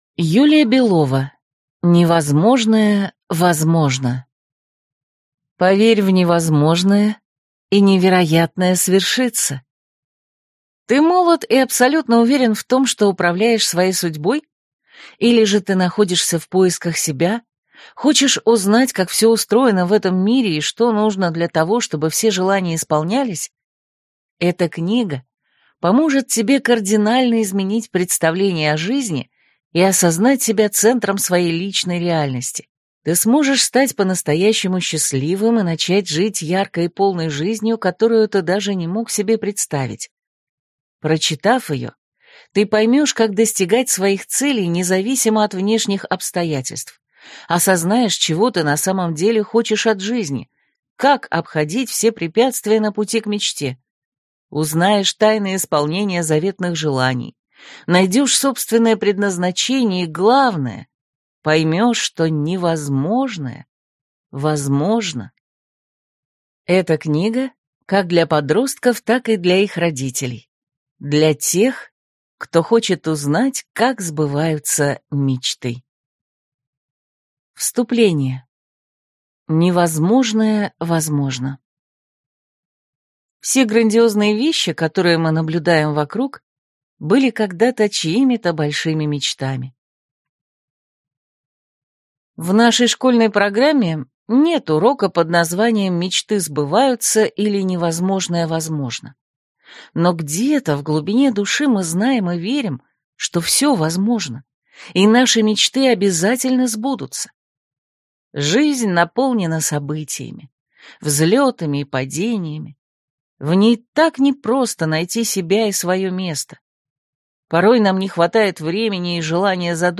Аудиокнига Невозможное возможно | Библиотека аудиокниг